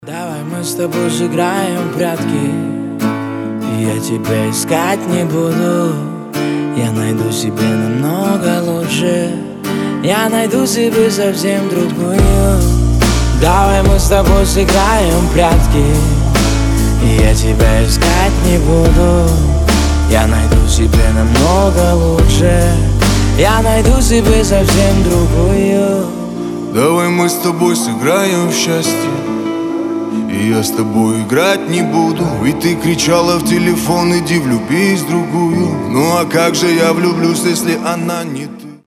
Романтические рингтоны
Гитара
Грустные , Красивый мужской голос